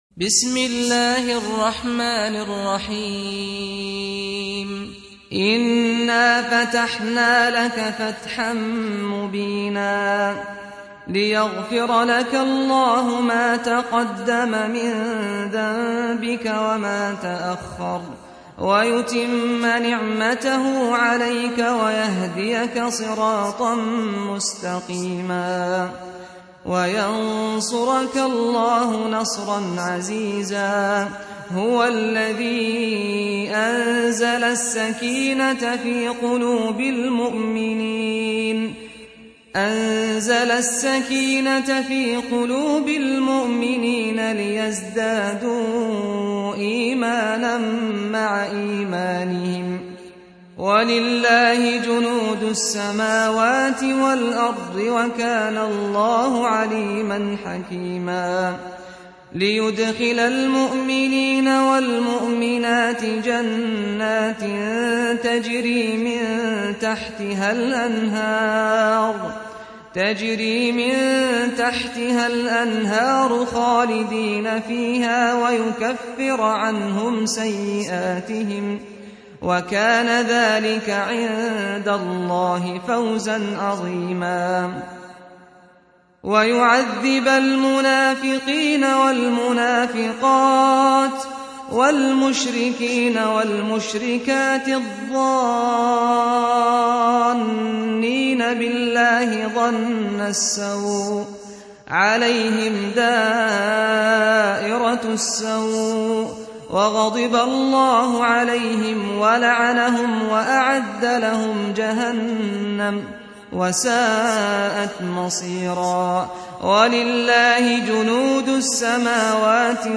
48. Surah Al-Fath سورة الفتح Audio Quran Tarteel Recitation
Surah Repeating تكرار السورة Download Surah حمّل السورة Reciting Murattalah Audio for 48.